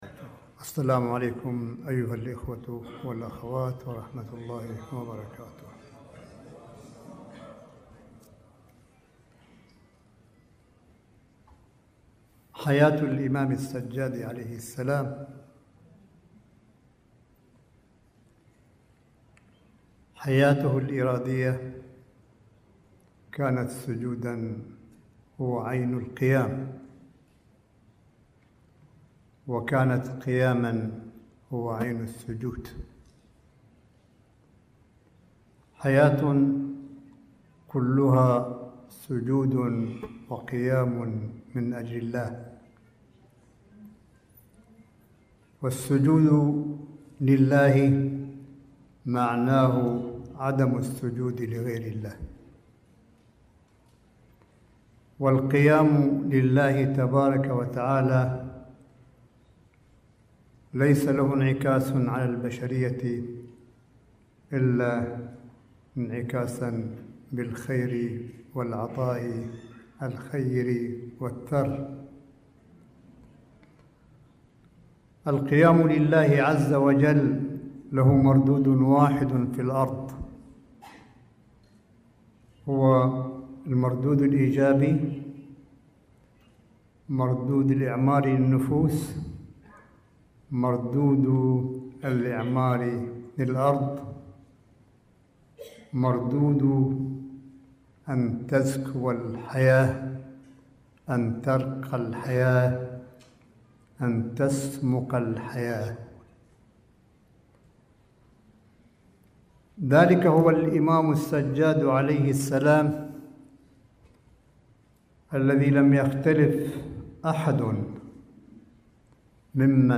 ملف صوتي لكلمة آية الله قاسم في مؤتمر الإمام السجاد الدولي في دورته التاسعة الذي أقيم بمنطقة بندر عباس جنوب الجمهورية الإسلامية في إيران – 25 سبتمبر 2019م